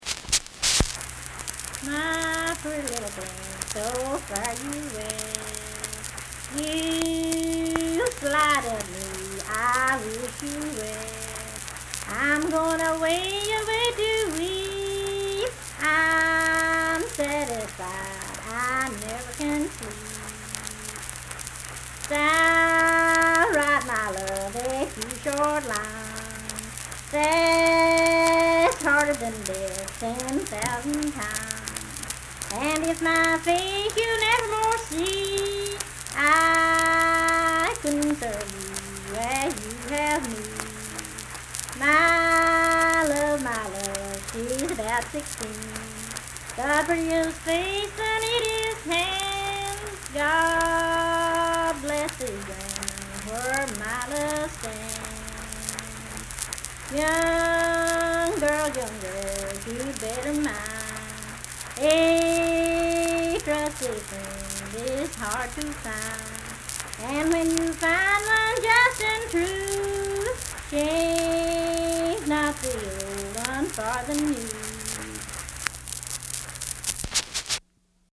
Unaccompanied vocal music
Verse-refrain, 4d(4).
Dance, Game, and Party Songs
Voice (sung)